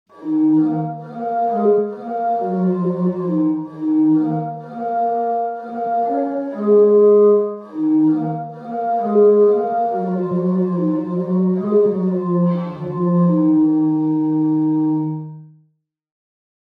Hydraulis
Skonstruował je grecki matematyk Ktesibios w III w. p.n.e. Ze względu na niespotykany w innych greckich i rzymskich instrumentach mocny i przenikliwy dźwięk, zyskały dużą popularność w pierwszych wiekach chrześcijaństwa, szczególnie w rzymskich cyrkach.
Podobnie jak w przypadku organów pneumatycznych (powietrznych), dźwięk jest wytwarzany przez powietrze wydmuchiwane przez piszczałki, z tym że siła do wytwarzania podmuchu nie pochodzi z miechów ani z prądu, tylko z wody.
Dźwięki instrumentów są brzmieniem orientacyjnym, wygenerowanym w programach:
Kontakt Native Instruments (głównie Factory Library oraz inne biblioteki) oraz Garritan (Aria Player).
Hydraulis.mp3